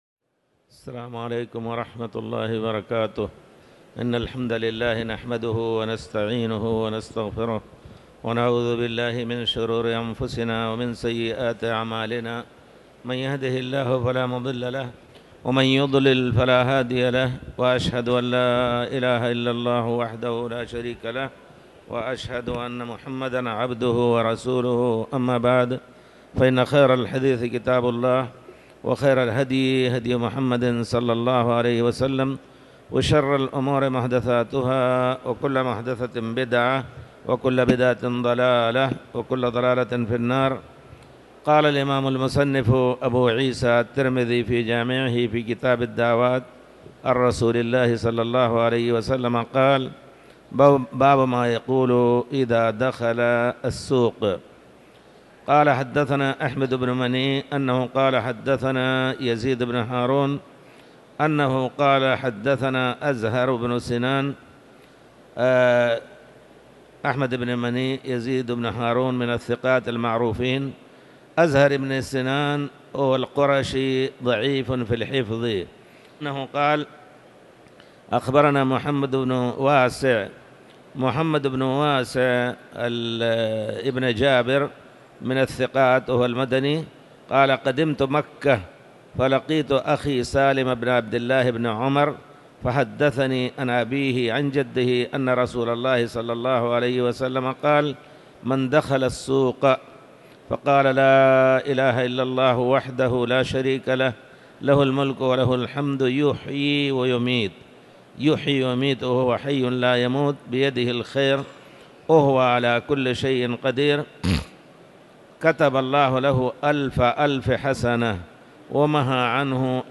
تاريخ النشر ١٨ جمادى الأولى ١٤٤٠ هـ المكان: المسجد الحرام الشيخ